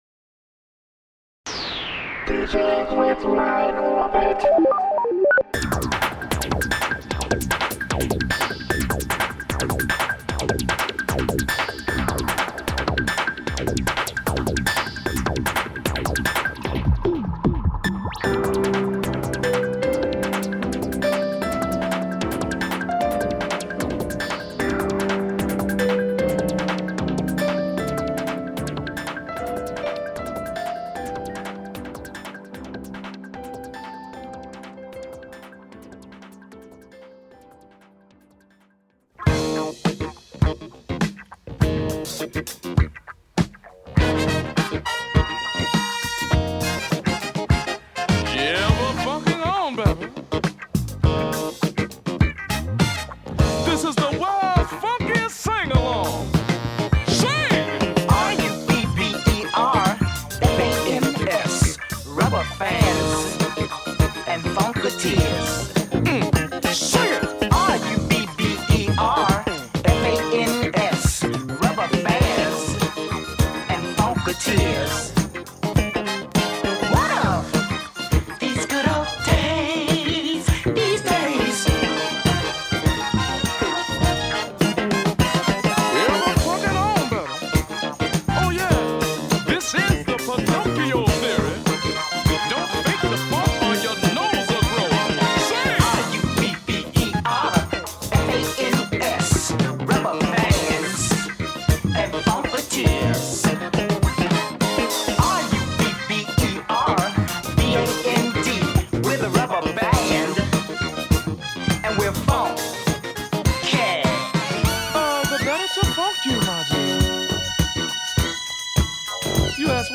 Wah Wah Effect is good for cold winter (part 2).